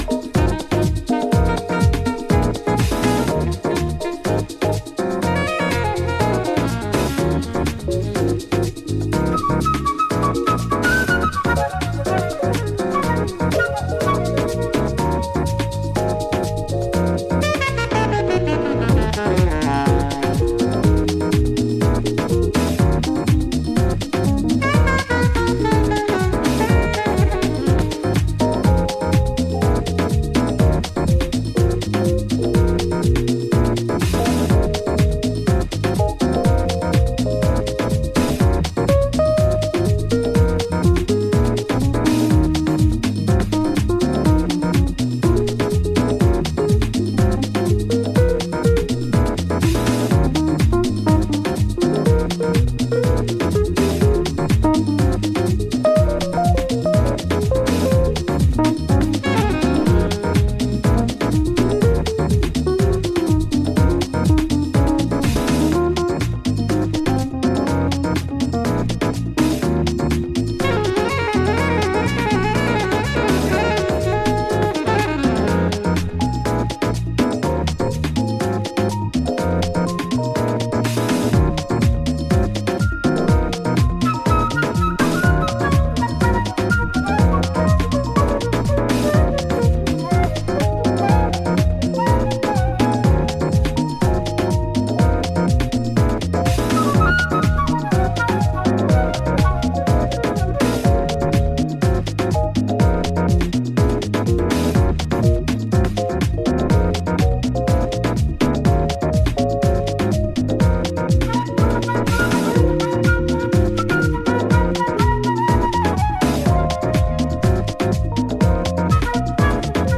トランペット、フルート、エレピなどのメロディーを配しながら温もりを帯びたジャズファンク・ハウスを繰り広げています。